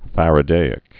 (fărə-dāĭk)